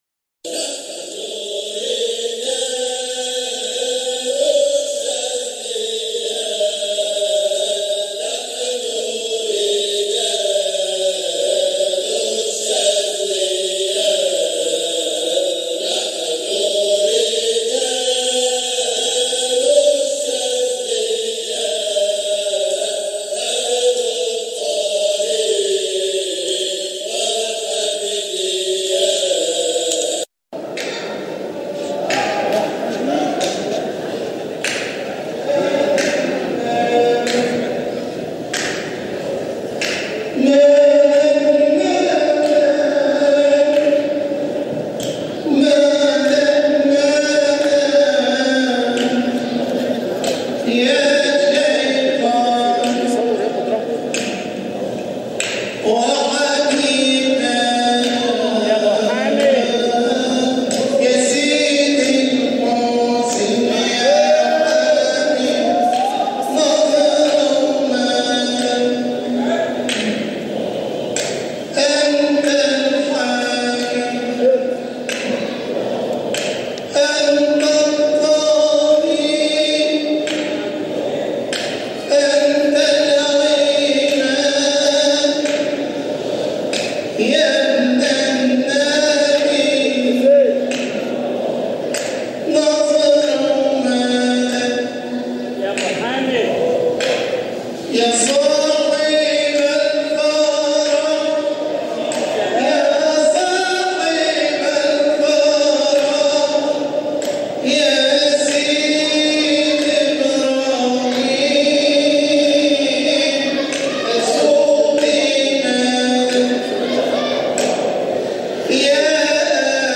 جزء من حلقة ذكر بمسجد سيدنا ابراهيم الدسوقي قدس سره 2018